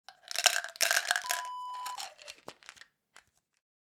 Pills to Container Wav Sound Effect
Description: The sound of pouring a handful of pills into a container and closing a lid
Properties: 48.000 kHz 24-bit Stereo
A beep sound is embedded in the audio preview file but it is not present in the high resolution downloadable wav file.
Keywords: pill, pills, medicine, tablet, container, bottle, plastic
pills-to-container-preview-1.mp3